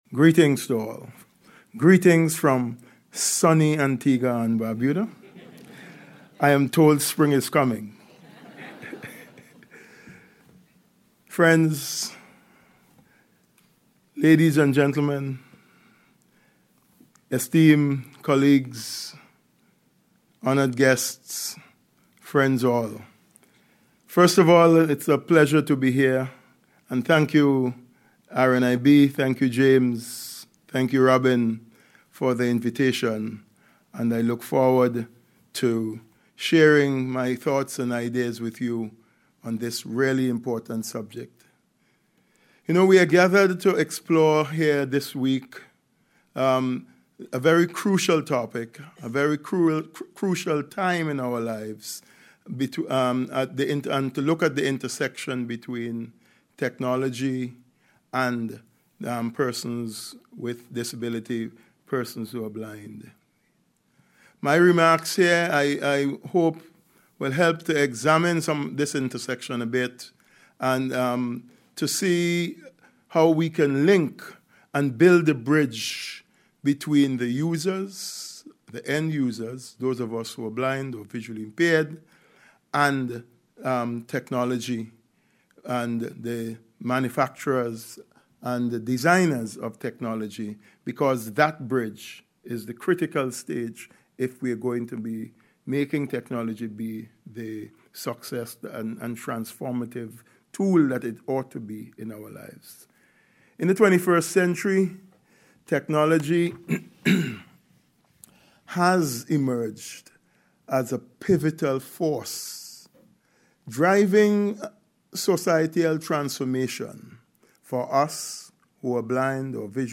Earlier this week, RNIB Scotland's Inclusive Design for Sustainability Conference returned to Glasgow for an exciting two-day event.